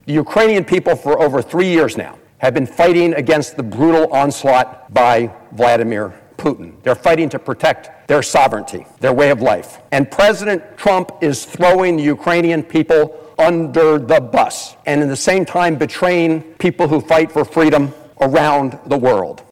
Maryland’s senior U.S. Senator Chris Van Hollen spoke on the Senate floor, decrying the exclusion of Ukrainian representatives in talks to end the war between Russia and the Ukraine.